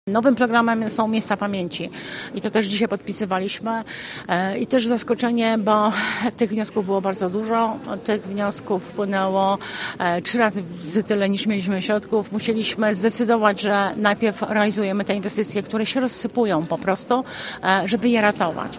Janina Ewa Orzełowska, członkini zarządu województwa mazowieckiego dodaje, że była to okazja do wdrożenia nowej inicjatywy: